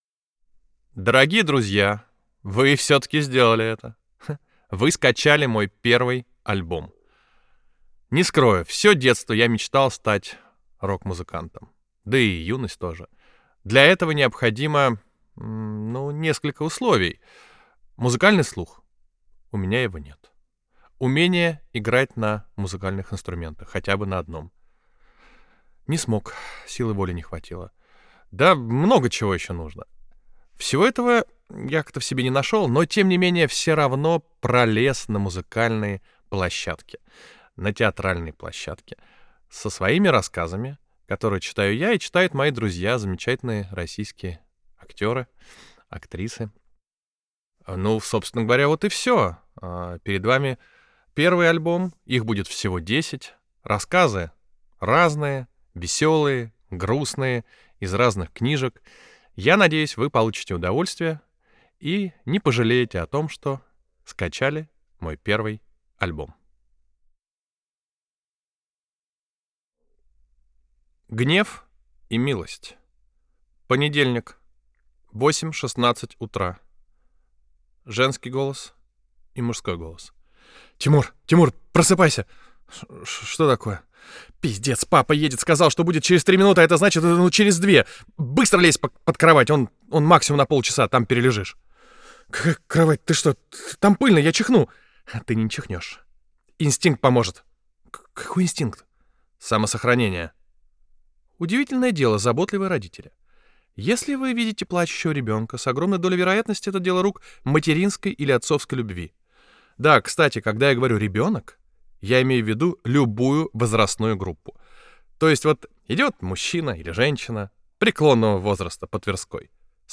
Аудиокнига iTsypkin. I da sukin syn. Volume I | Библиотека аудиокниг